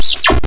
WIP zap death sound
zap_wip.ogg